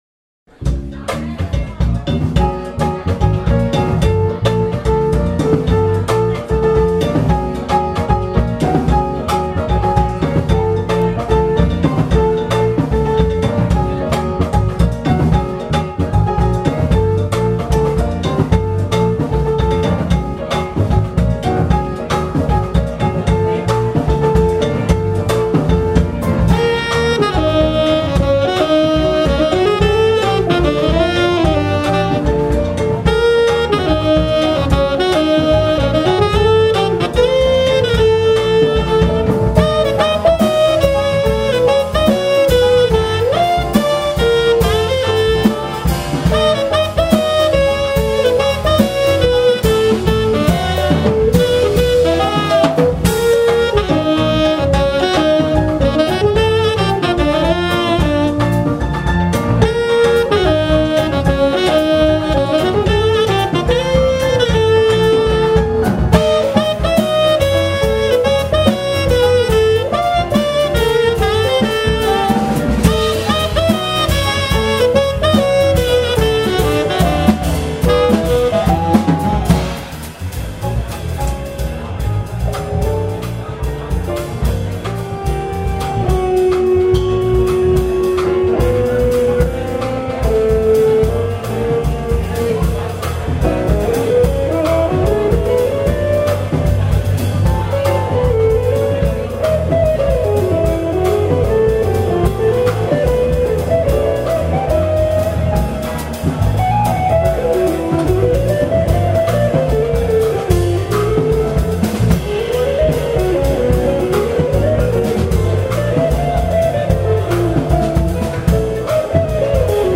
drums
bass
tenor sax
guitar
piano